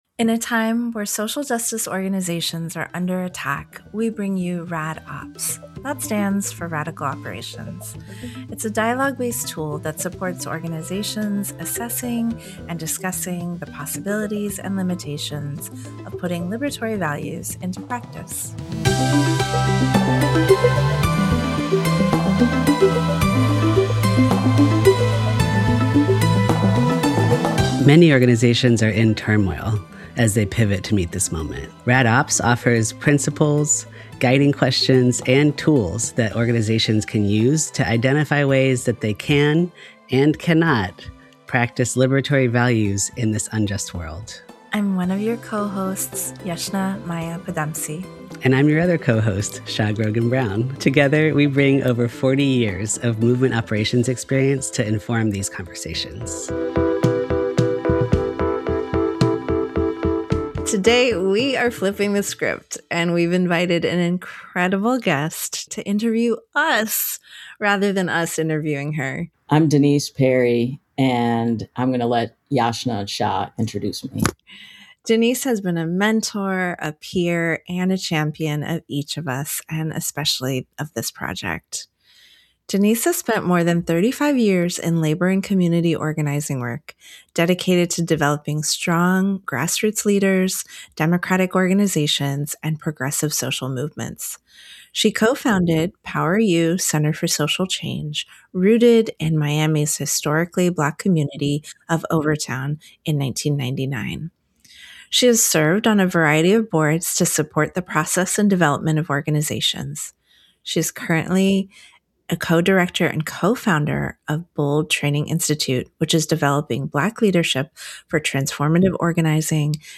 The Rad Ops Podcast is one component of a dialogue-based tool that supports organizations assessing and discussing the possibilities and limitations of putting liberatory values into practice. Each guest will share their Rad Ops lineages, their expertise on today’s pressing operations questions and leave listeners with resources and discussion questions to take back to your teams.